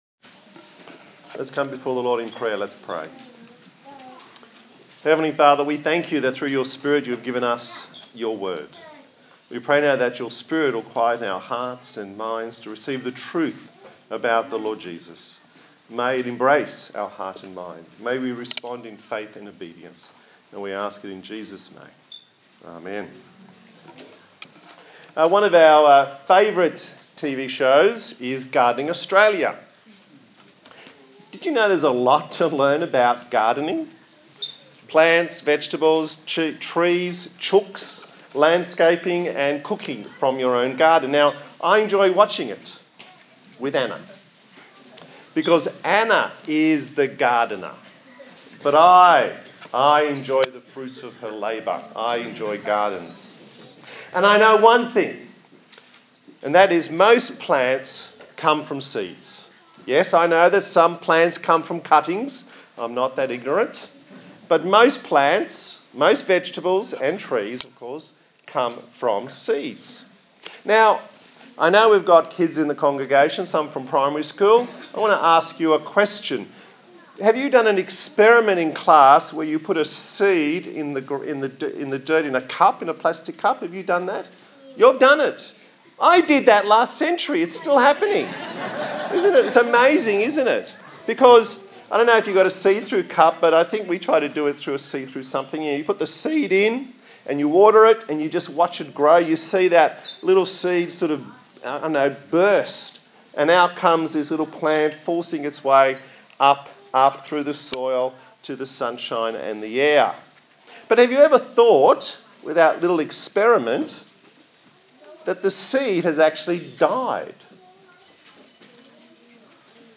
Service Type: Sunday Morning A sermon in the series on the book of John